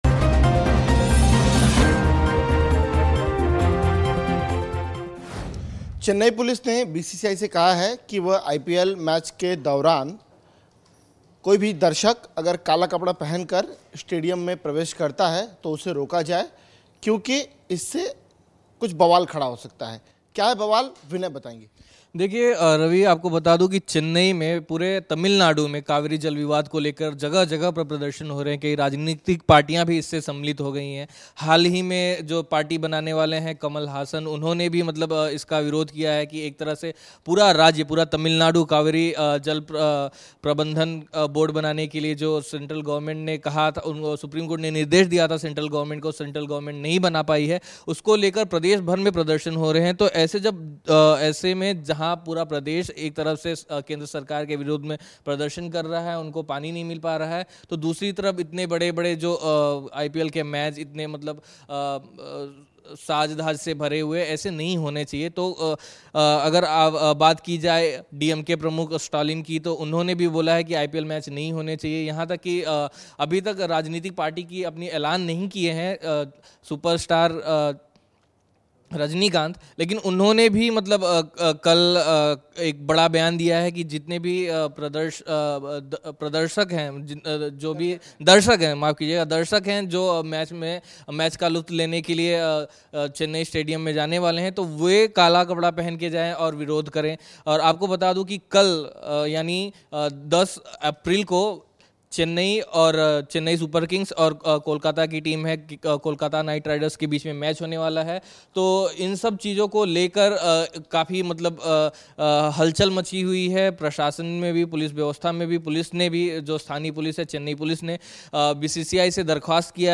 News Report / कावेरी जल विवाद: आईपीएल में काले कपड़े पहनकर आने पर रोक